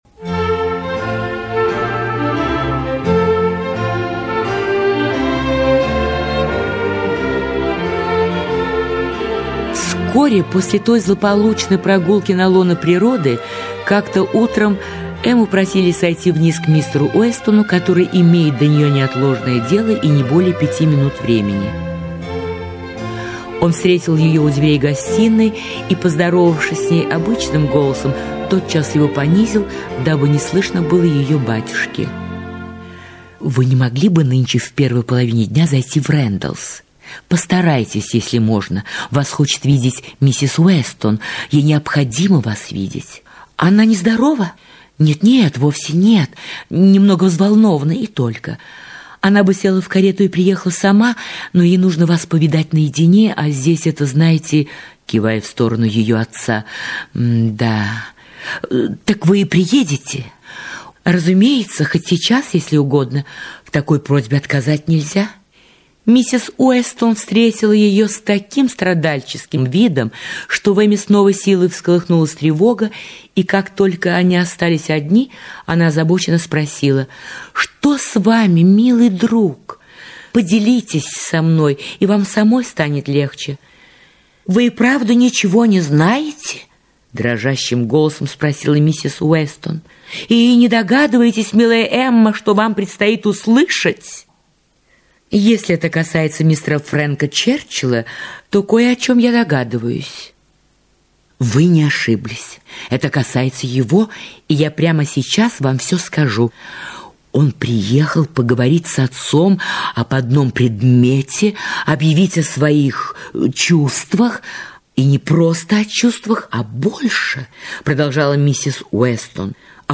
Аудиокнига Эмма
Качество озвучивания весьма высокое.